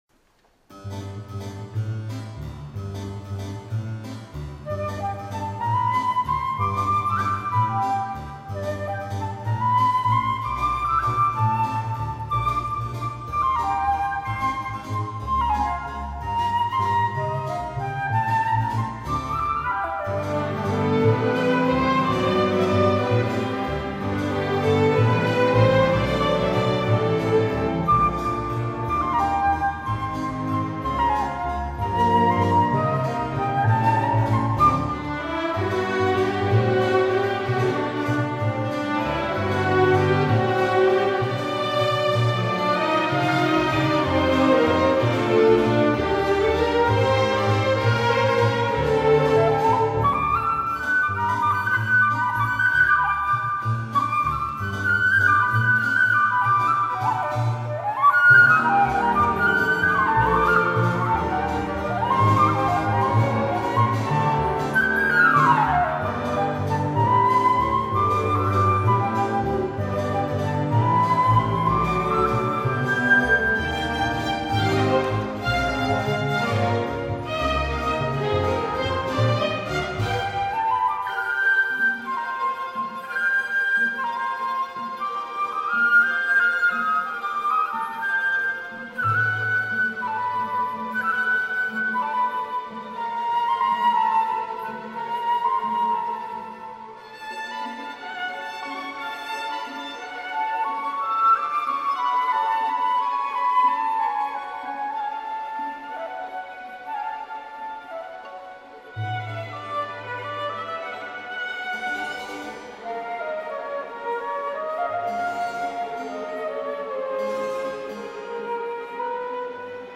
waltz_kammerorchester
waltz_kammerorchester.mp3